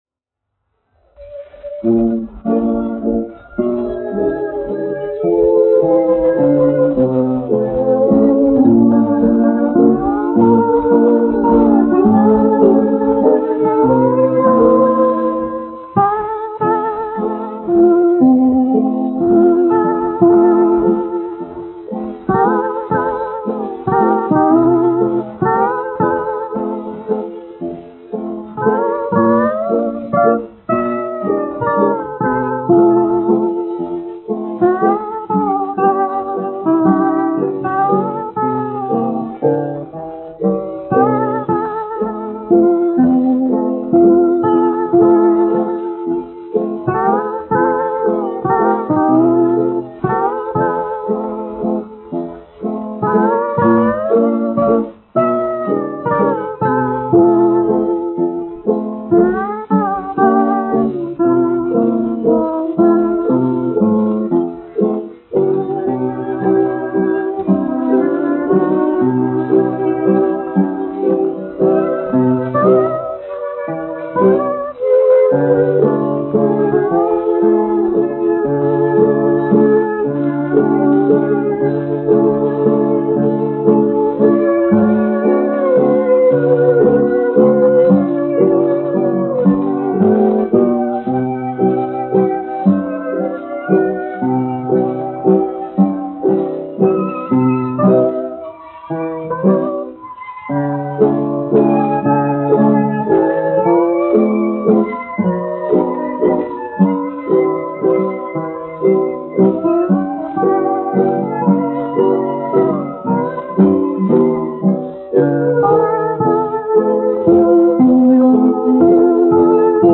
1 skpl. : analogs, 78 apgr/min, mono ; 25 cm
Populārā instrumentālā mūzika
Latvijas vēsturiskie šellaka skaņuplašu ieraksti (Kolekcija)